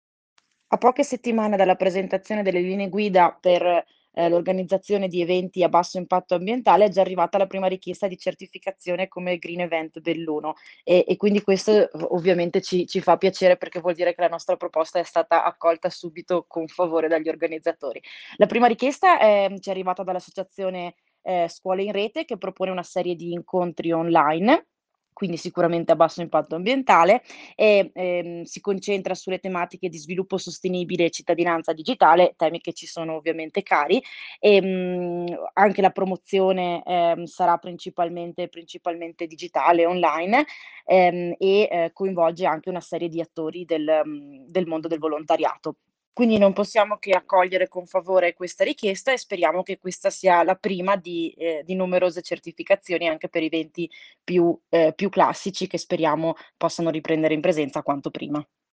YUKI D’EMILIA, ASSESSORE ALLE MANIFESTAZIONI DEL COMUNE DI BELLUNO